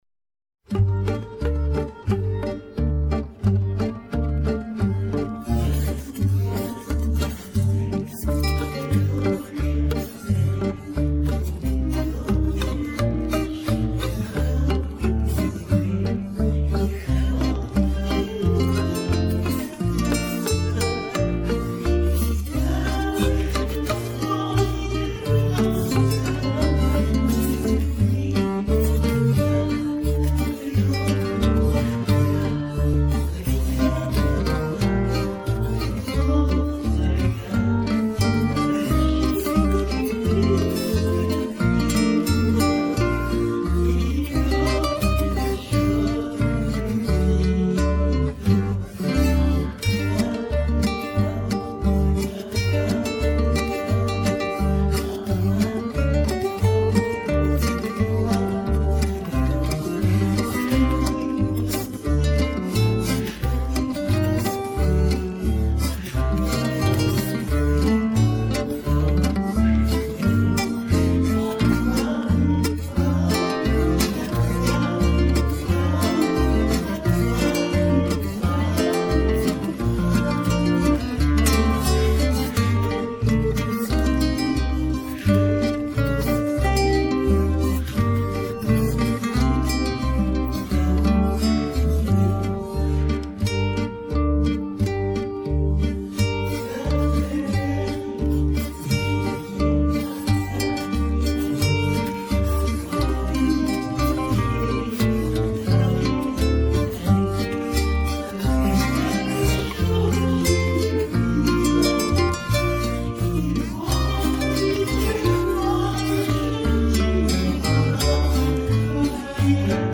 Нашла только такой вариант в разделе-Минусовки.